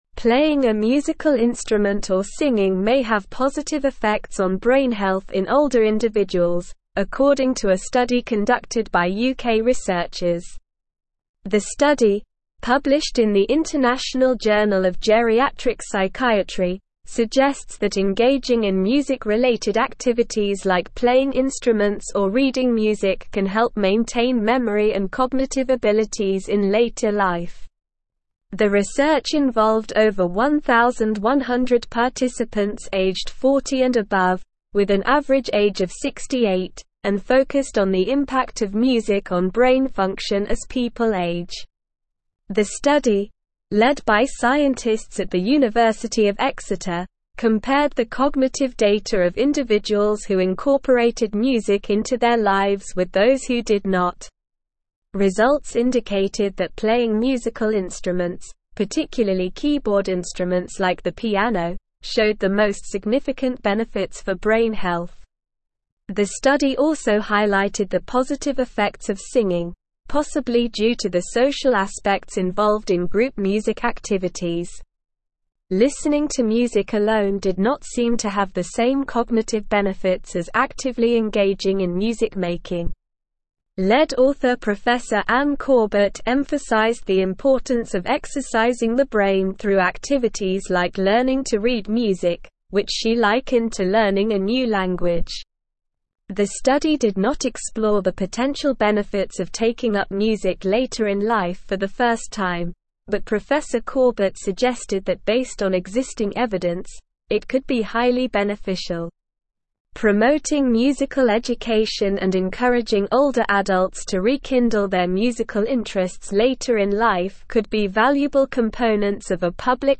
Slow
English-Newsroom-Advanced-SLOW-Reading-Music-and-Brain-Health-Benefits-of-Playing-Instruments.mp3